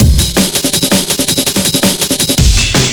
cw_amen20_164.wav